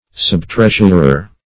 Search Result for " subtreasurer" : The Collaborative International Dictionary of English v.0.48: Subtreasurer \Sub*treas"ur*er\, n. The public officer who has charge of a subtreasury.